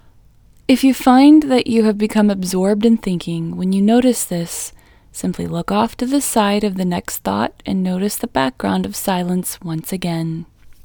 LOCATE IN English Female 23